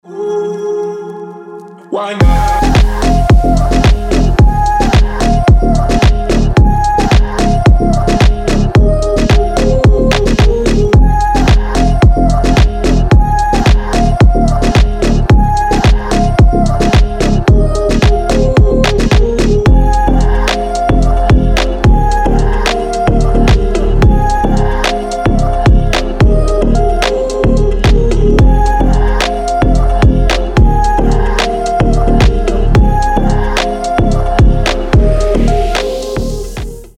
• Качество: 320, Stereo
мужской вокал
deep house
Electronic
спокойные